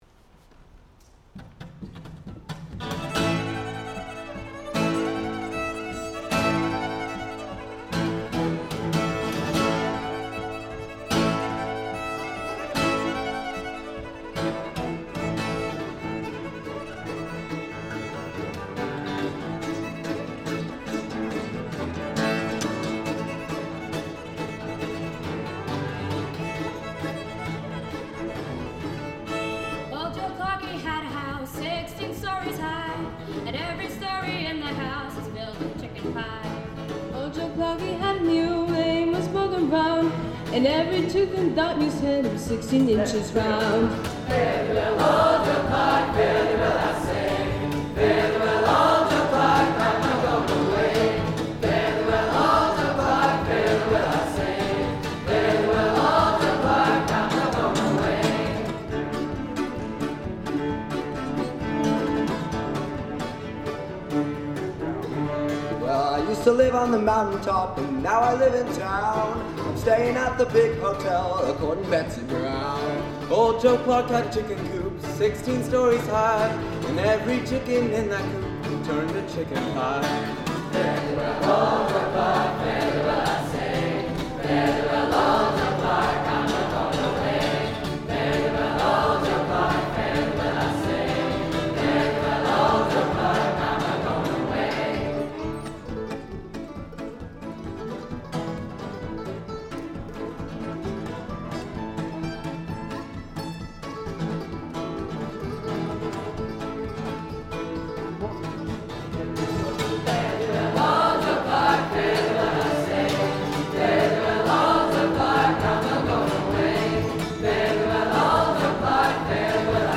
Chamber, Choral & Orchestral Music
Chorus